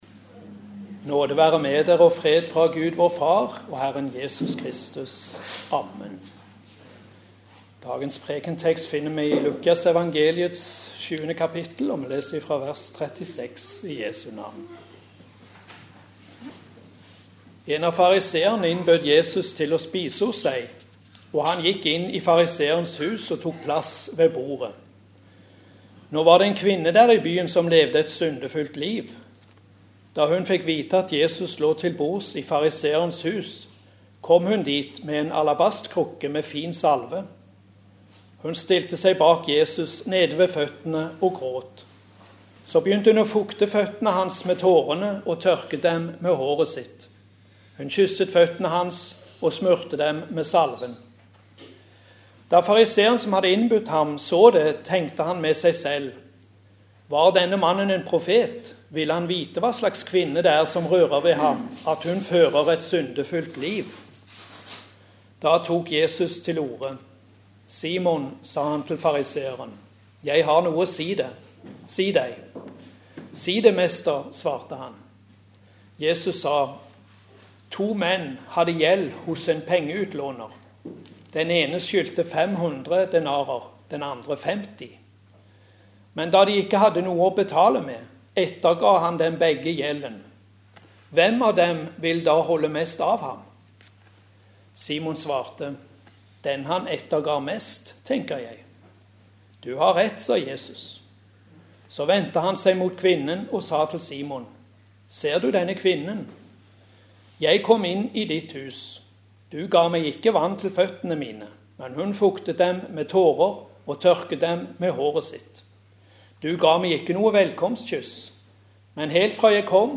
Preken på 2. søndag i faste 2013